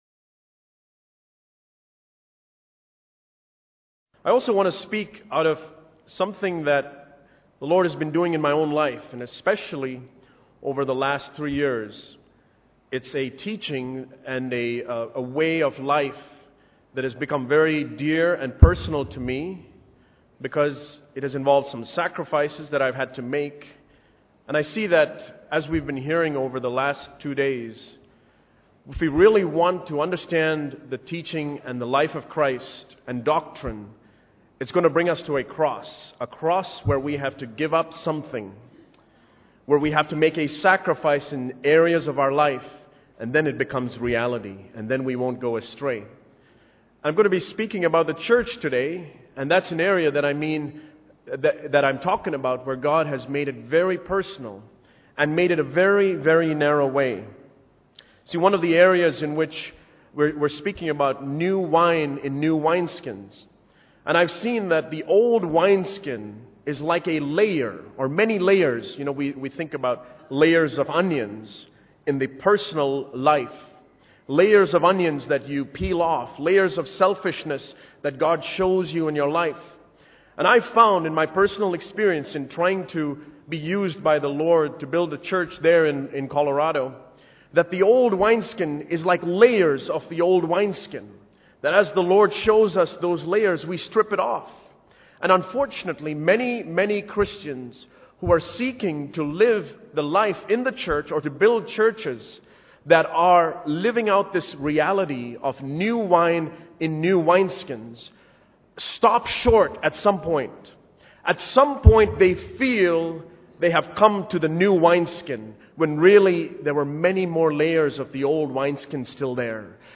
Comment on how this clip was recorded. The Congregation, The Club And The Church New Wine In New Wineskins The live streamed messages spoken during the 2011 Bangalore Conference.